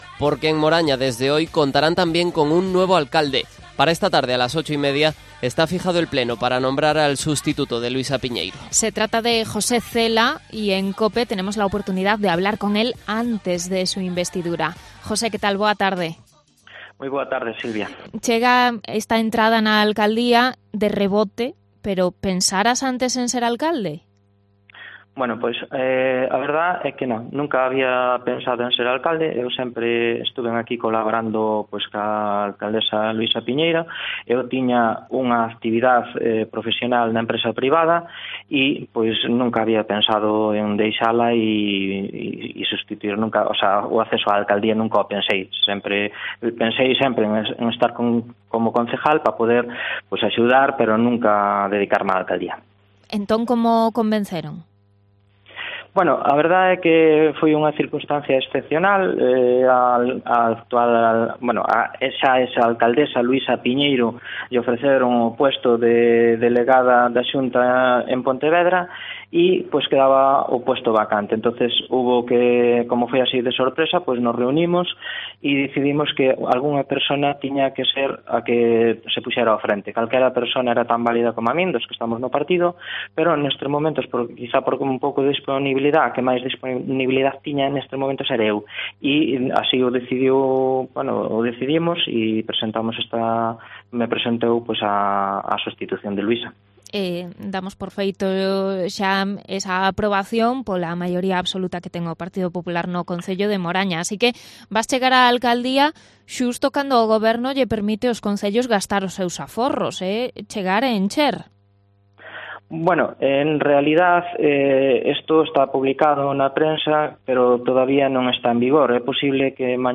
Entrevista con José Cela antes de ser investido alcalde de Moraña